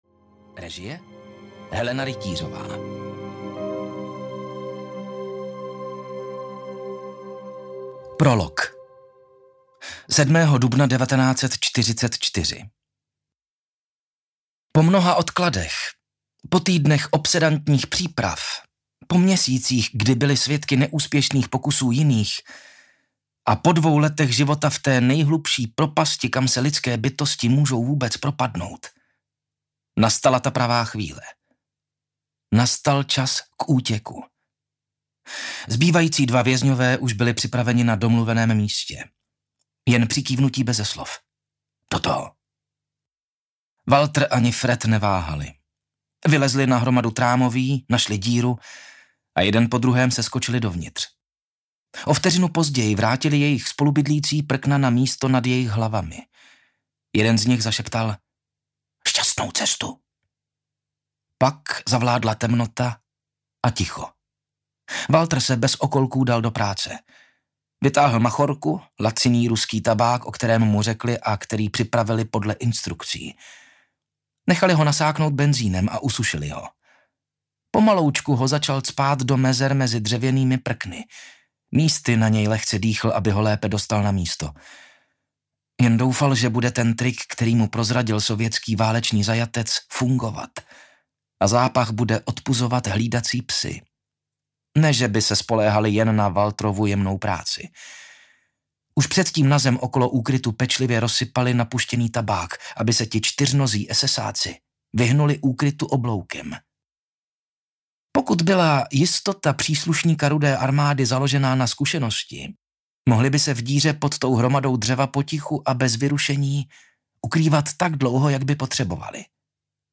Únik z Osvětimi audiokniha
Ukázka z knihy